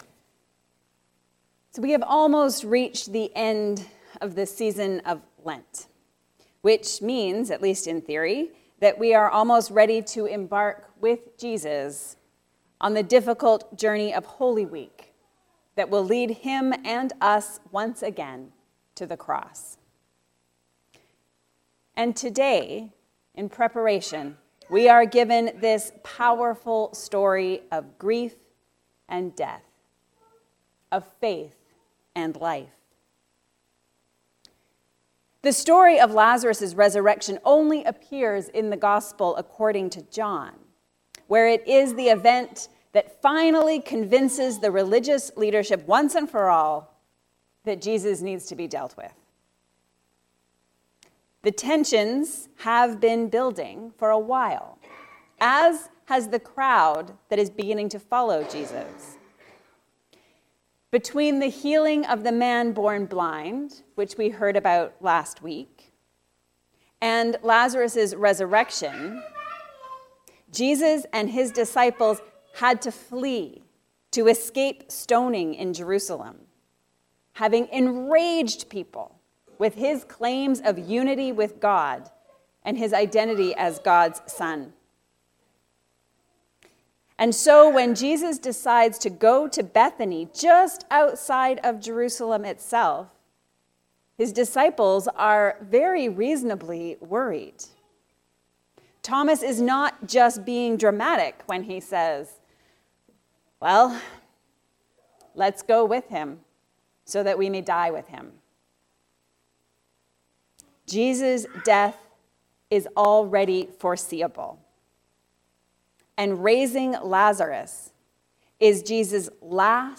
The costliness of death. A sermon on John 11:1-45